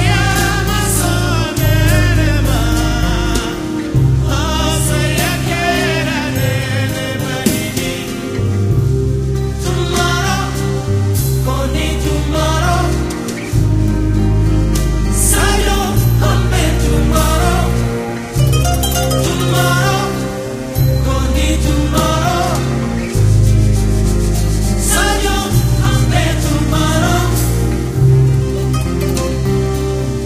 Műfajokpopzene